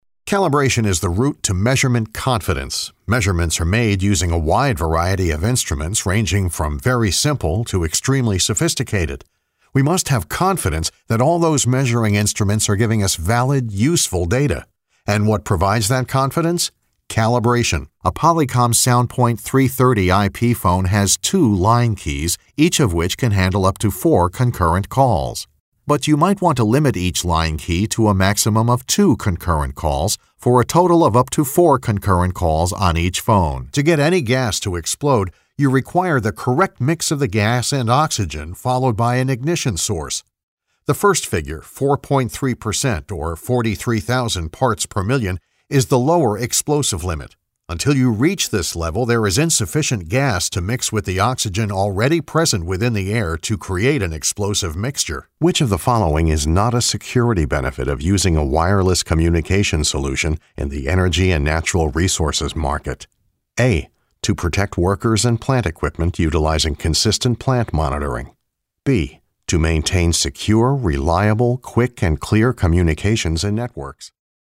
Highly experienced, credible and versatile Voice Actor/Narrator.
Sprechprobe: eLearning (Muttersprache):